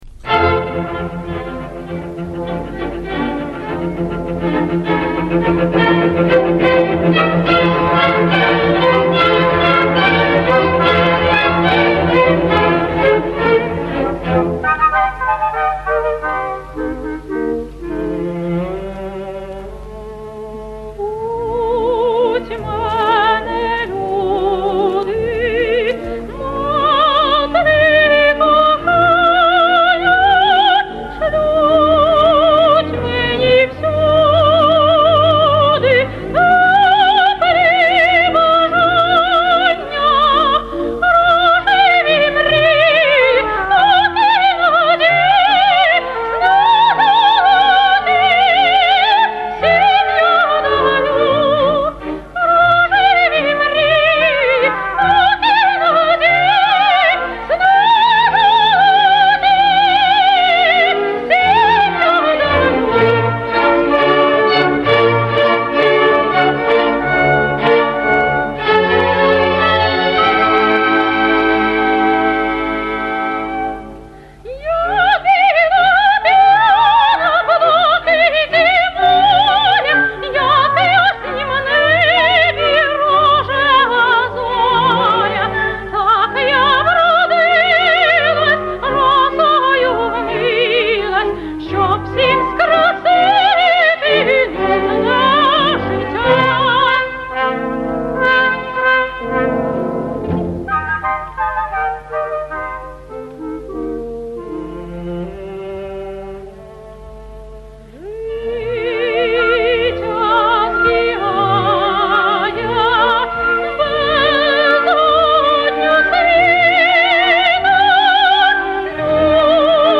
Запис  1949  року.